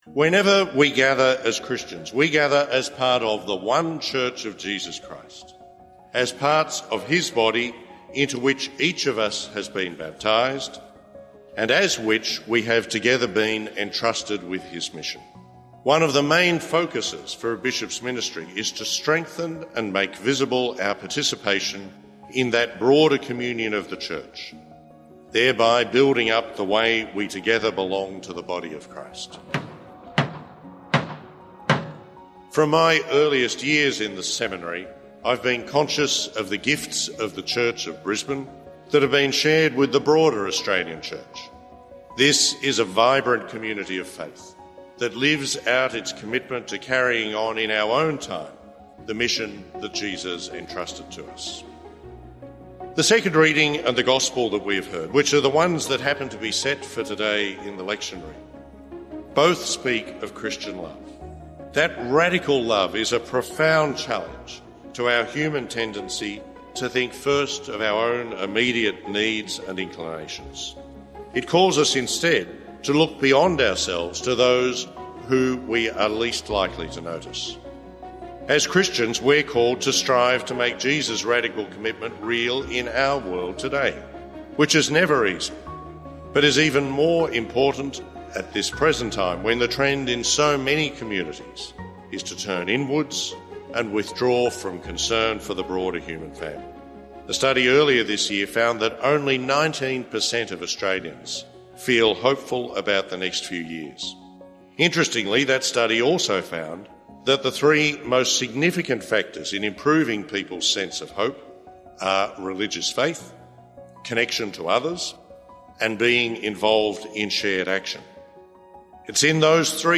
Archbishop Shane Mackinlay Installation Highlights